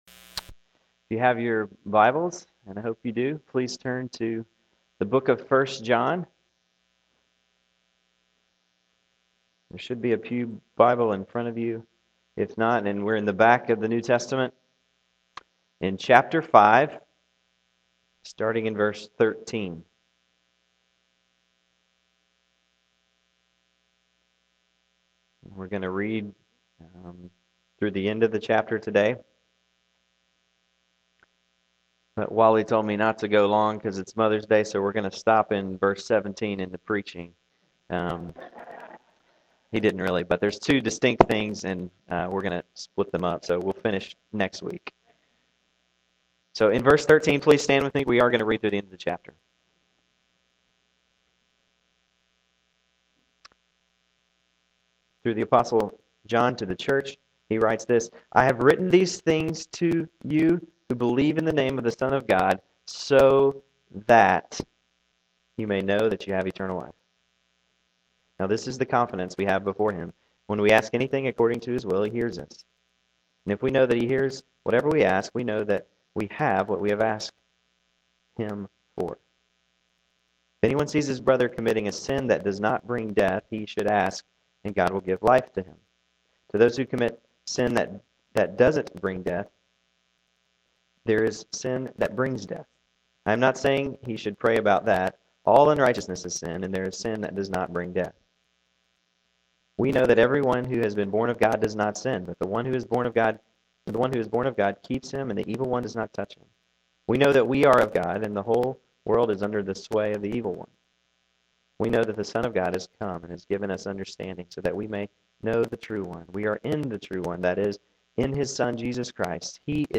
Here are some sermon audio files to bring us up to date.